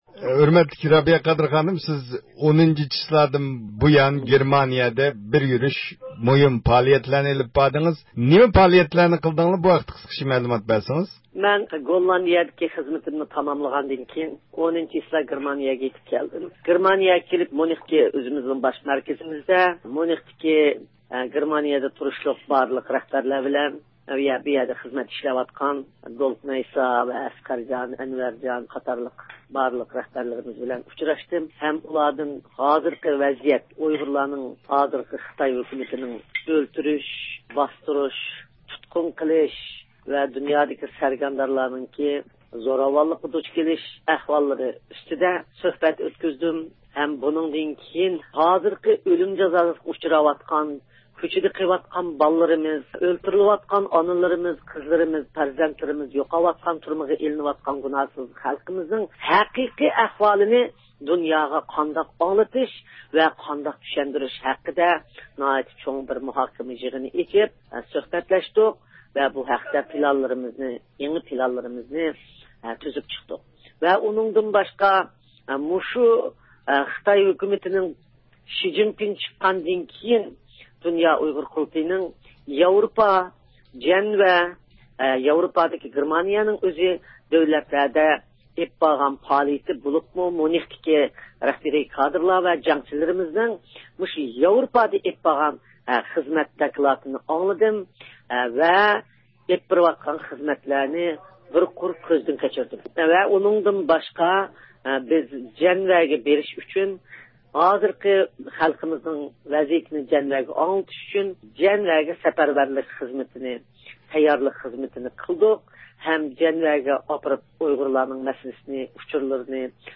بىز بۇ ھەقتە تېخىمۇ تەپسىلىي مەلۇمات ئېلىش ئۈچۈن د ئۇ ق رەئىسى رابىيە قادىر خانىم ۋە ئىجرائىيە كومىتېتى مۇدىرى دولقۇن ئەيسا ئەپەندىلەر بىلەن سۆھبەت ئېلىپ باردۇق.